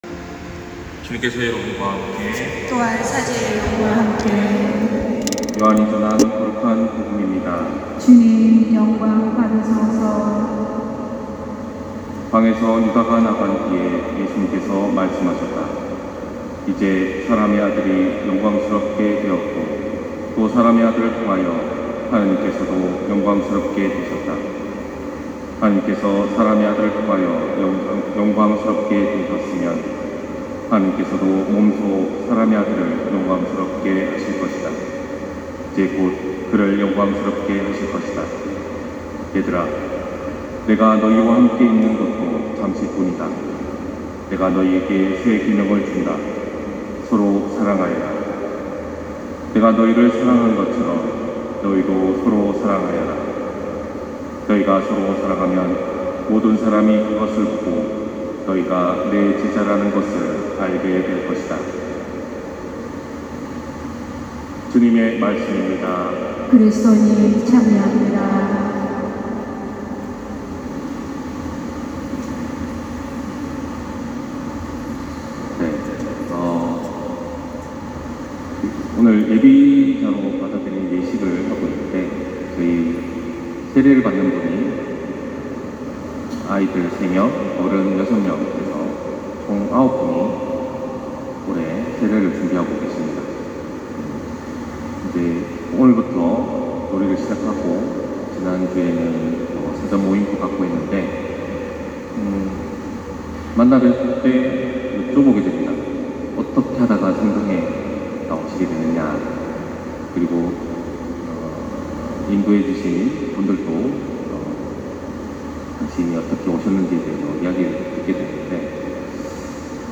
250517신부님강론말씀